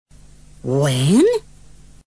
Pronunciación de exclamaciones: voz ascendente (II)
Ahora escucharás cuatro exclamaciones en preguntas (voz en ascenso), cada una de las cuales responde a un comentario.